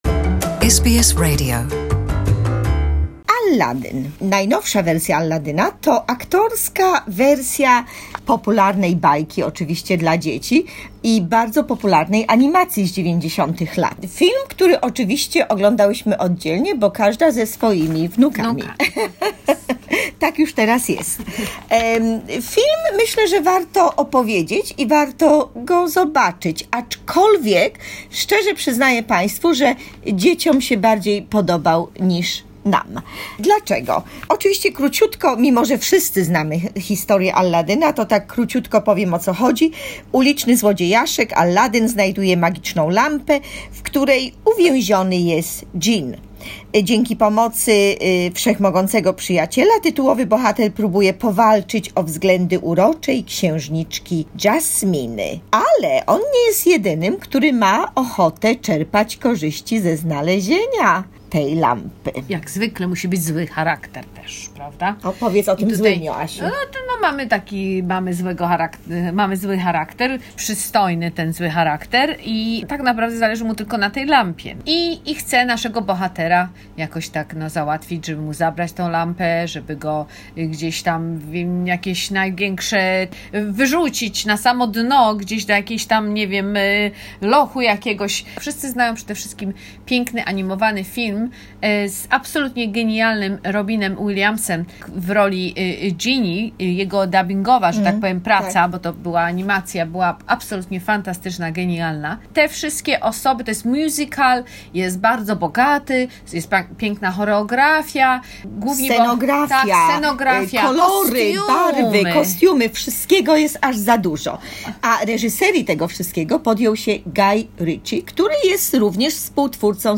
Movie review: Aladdin